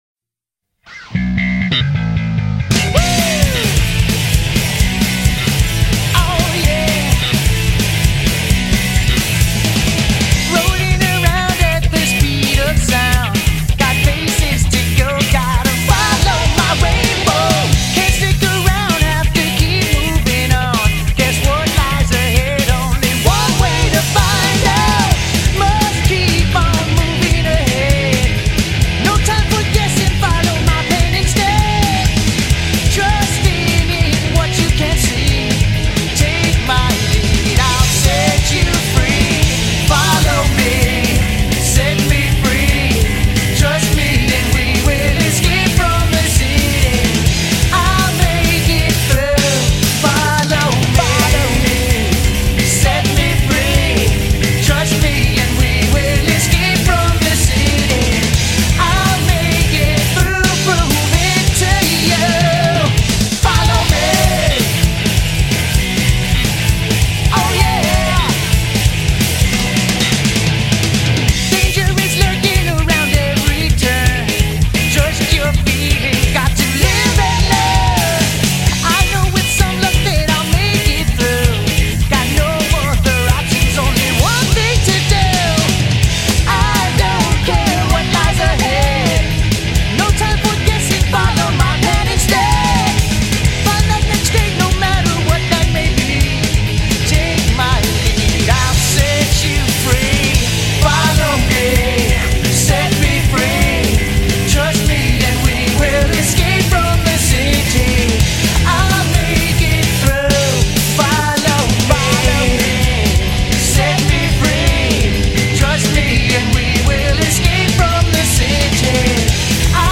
BPM130
Audio QualityCut From Video